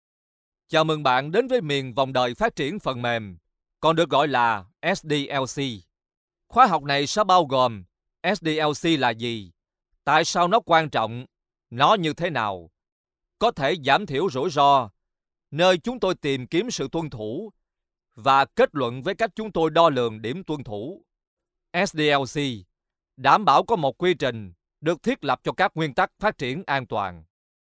Vietnamese voice over